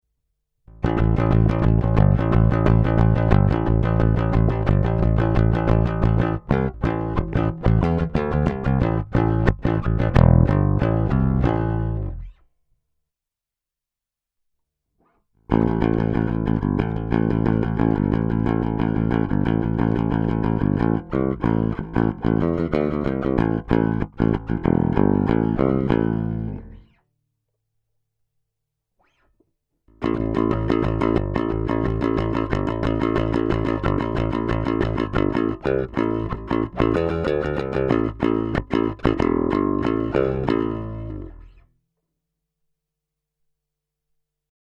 Yamaha Super Bass Pickups klingen sehr dünn
Die beiden PUs einzeln klingen Hammer, nur beide zusammen sind echt schrecklich und eigentlich auch im Bandkontext unbrauchbar.
Der Yamaha wäre vermutlich mein Lieblingsbass aber das macht mir gerade noch nen Strich durch die Rechnung Im Soundbeispiel hört ihr zunächst nur den Neck PU, dann nur den Bridge Pu und dann beide. Ganz einfach in den laptop eingespielt ohne Plugins / Bearbeitung usw Danke und liebe Grüße!